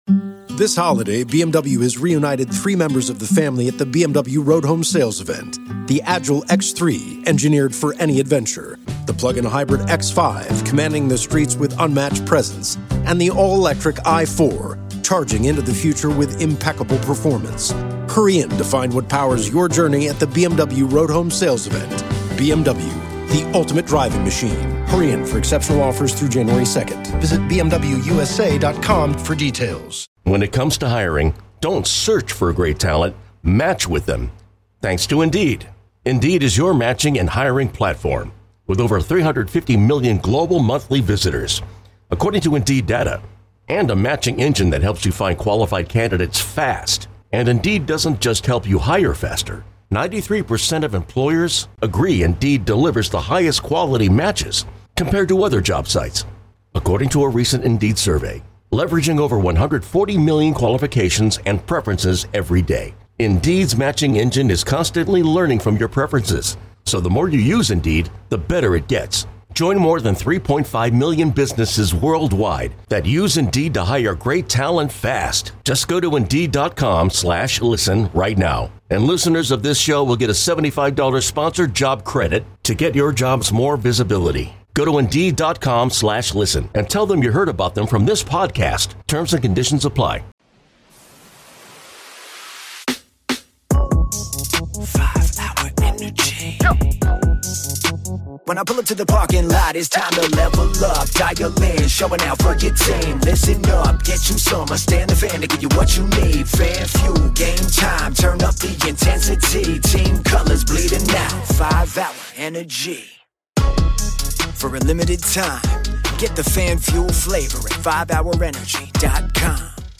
(HOUR 5): Open Mic Hour.